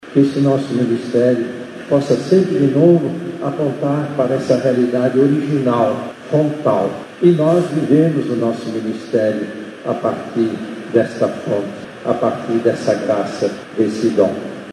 Após a peregrinação, o clero, juntamente com a comunidade, participou da Celebração Eucarística, presidida pelo arcebispo de Manaus, cardeal Leonardo Steiner. Em sua homilia, Dom Leonardo destacou a graça de poder retomar a motivação e renovar o ministério.